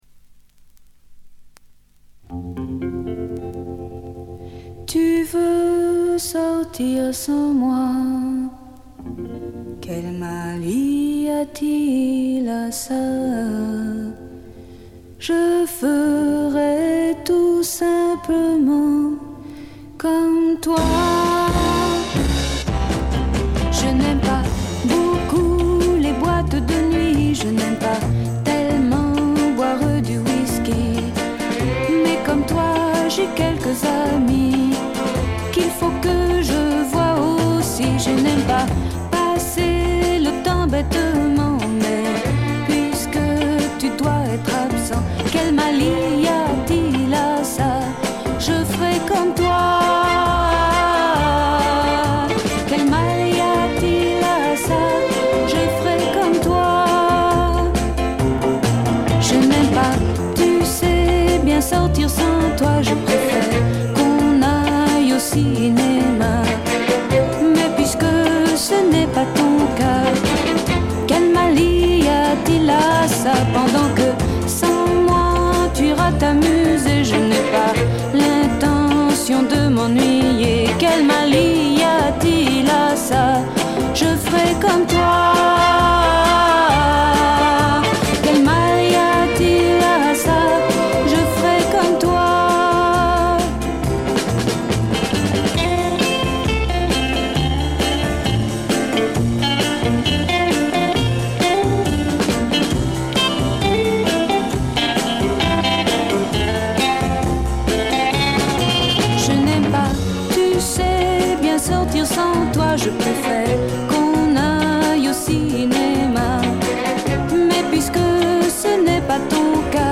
モノラル盤。
試聴曲は現品からの取り込み音源です。